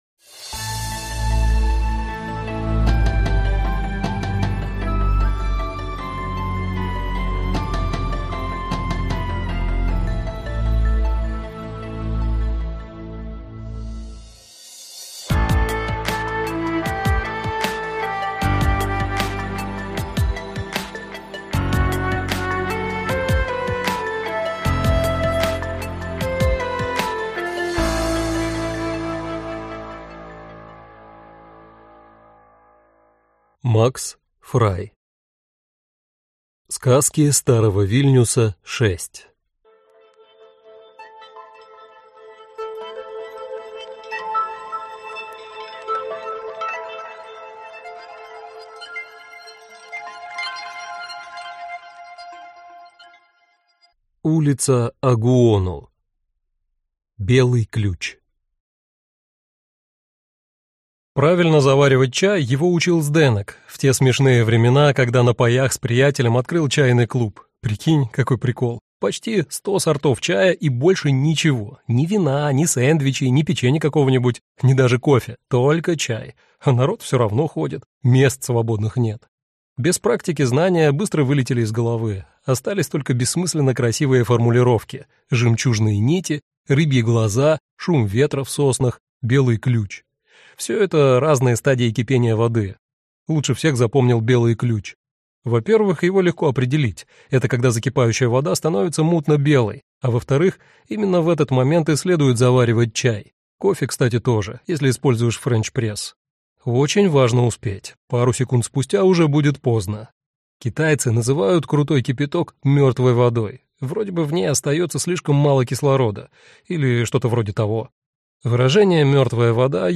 Аудиокнига Сказки старого Вильнюса VI - купить, скачать и слушать онлайн | КнигоПоиск